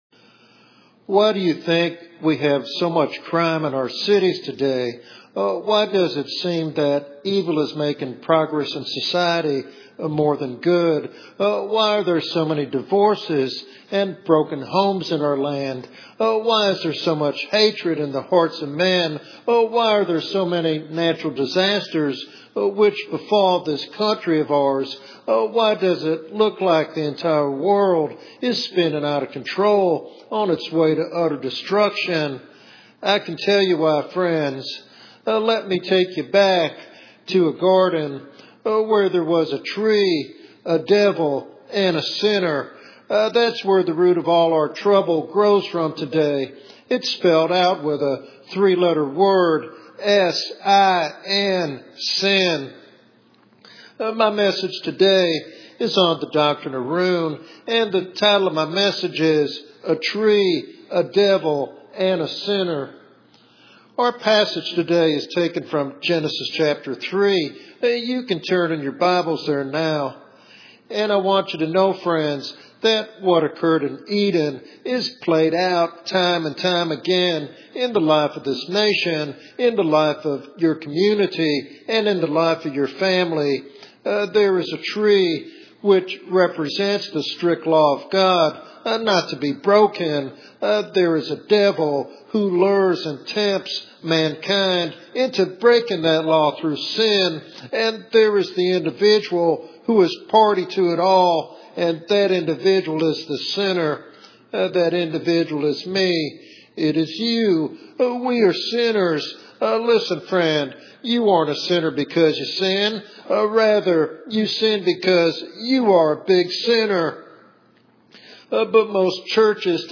In this powerful evangelistic sermon